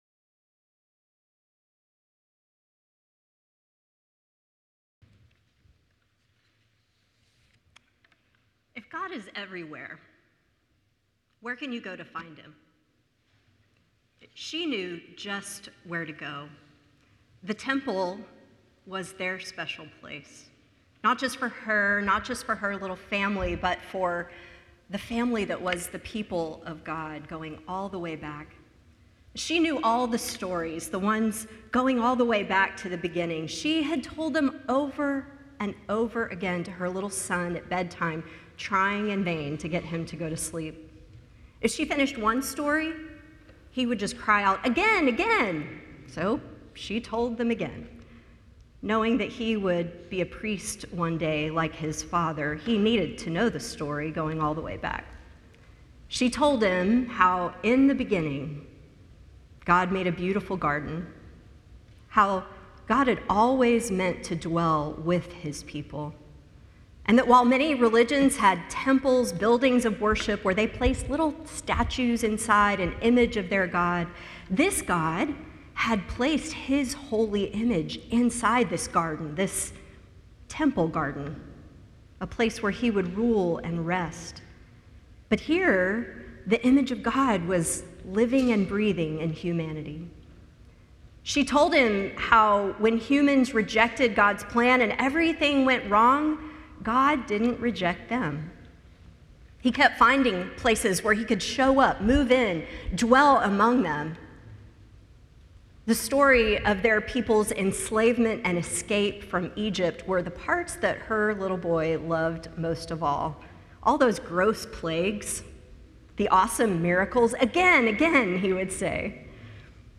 The following service took place on Thursday, October 24, 2024.